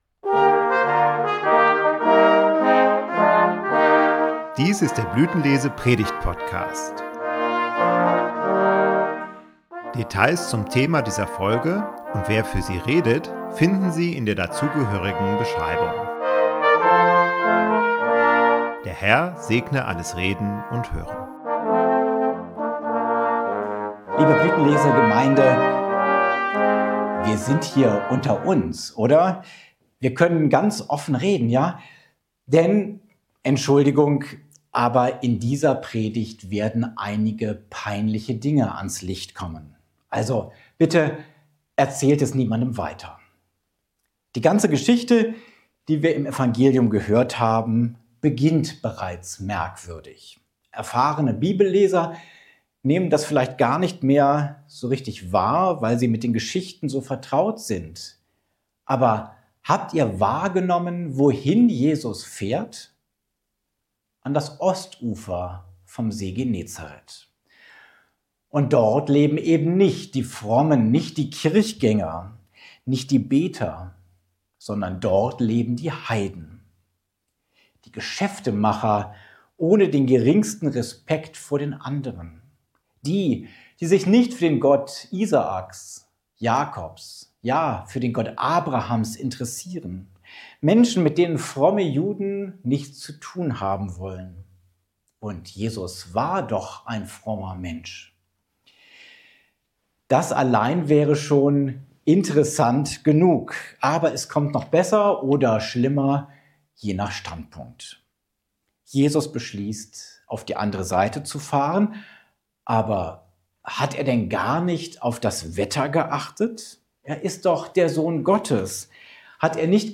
Blütenlese Predigt-Podcast